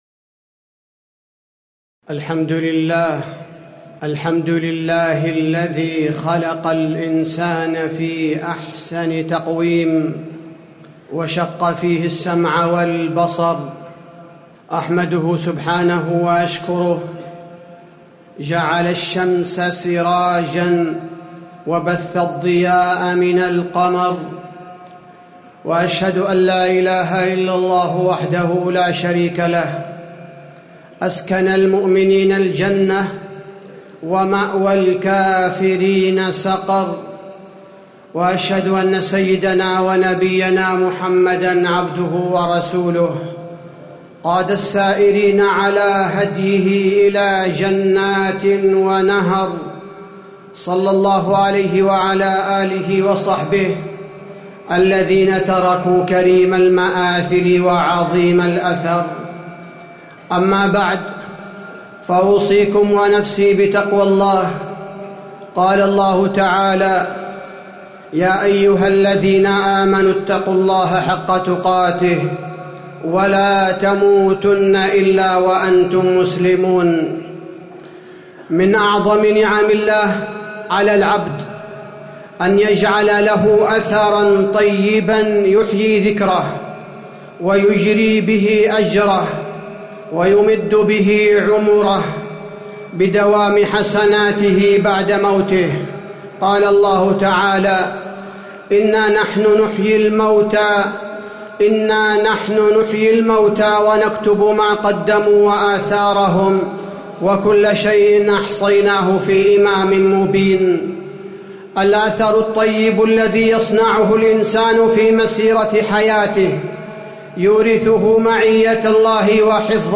تاريخ النشر ٢٤ رجب ١٤٣٨ هـ المكان: المسجد النبوي الشيخ: فضيلة الشيخ عبدالباري الثبيتي فضيلة الشيخ عبدالباري الثبيتي الأثر الطيب الذي يصنعه الإنسان في حياته